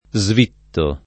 Svitto [ @ v & tto ]